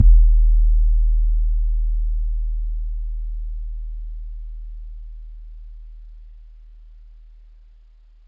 Southside 808 (20).wav